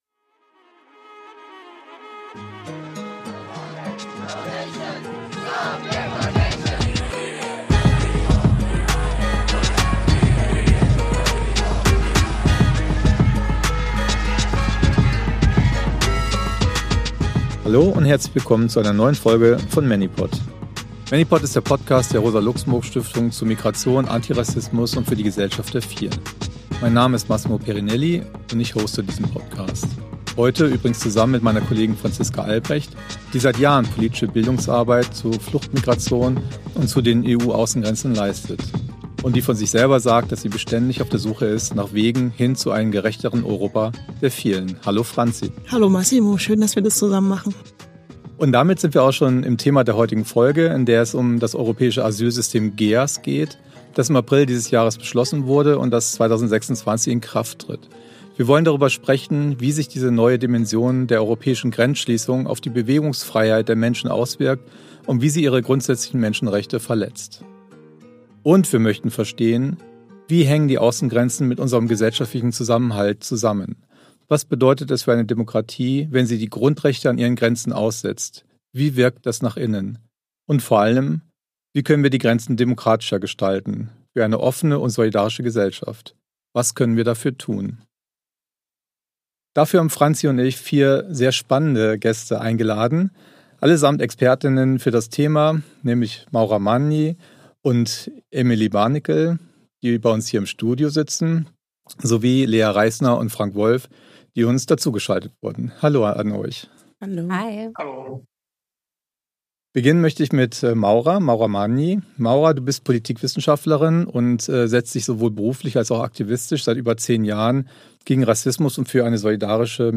Der ManyPod ist ein Gesprächs-Podcast, der euch die Kämpfe der Migration um die Ohren hauen möchte. Wir unterhalten uns mit Freund*innen und Genoss*innen aus der Bewegung, der Wissenschaft, Kultur und Politik darüber, wie wir die Verhältnisse zum Tanzen bringen können.